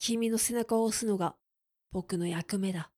クール男性
dansei_kiminosenakawoosunogabokunoyakumeda.mp3